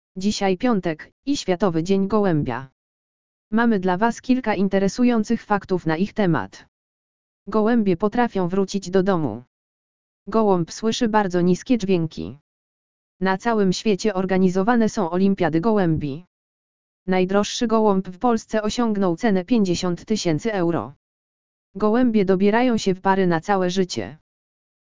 AUDIO LEKTOR ŚWIATOWY DZIEŃ GOŁĘBIA
audio_lektor_swiatowy_dzien_golebia.mp3